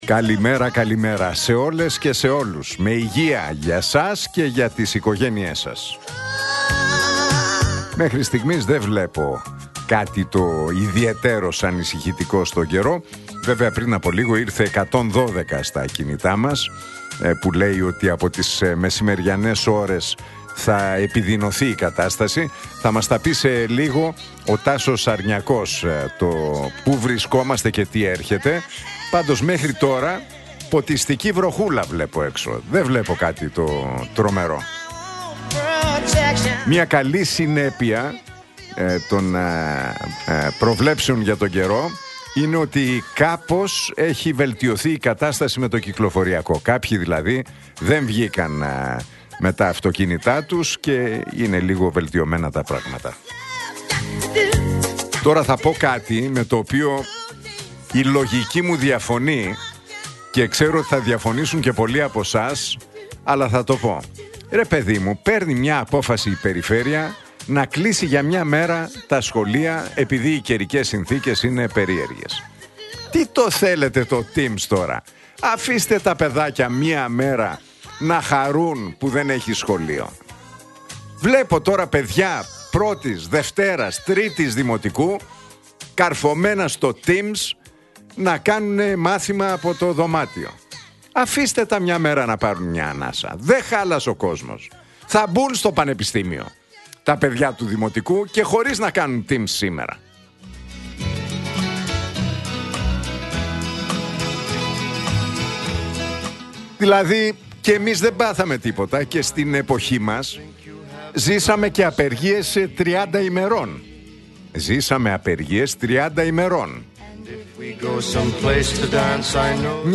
Ακούστε το σχόλιο του Νίκου Χατζηνικολάου στον ραδιοφωνικό σταθμό Realfm 97,8, την Τετάρτη 21 Ιανουαρίου 2026.